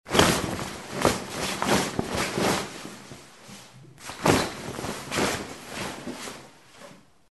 Звуки ткани
Огромный кусок ткани пытаются разгладить